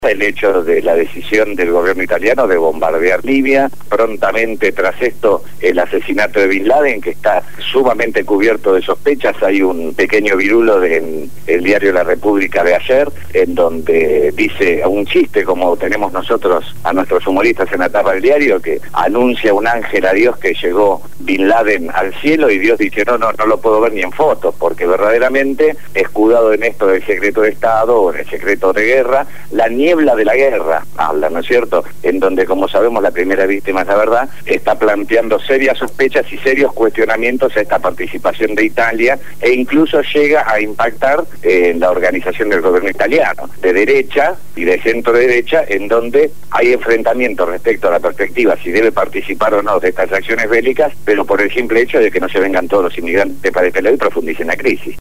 habló desde Italia y dio un completo informe de la situación crítica que vive el país europeo.